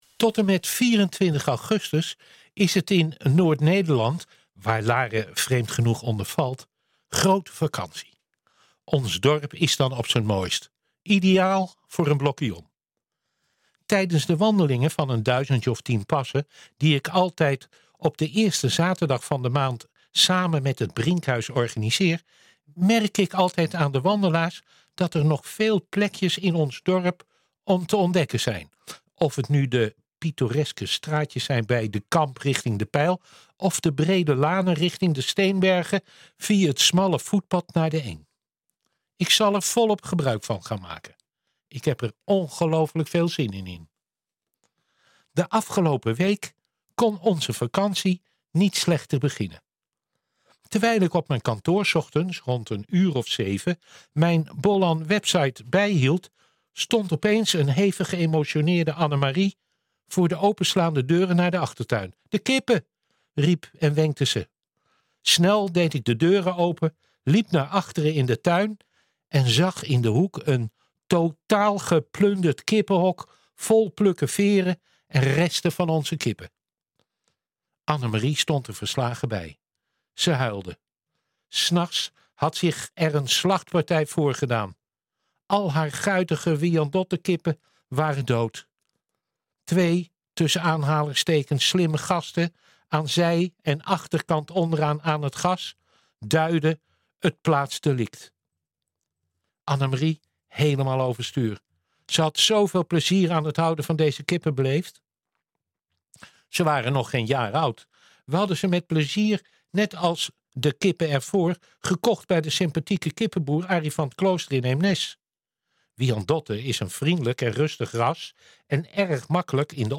NH Gooi Zaterdag - Column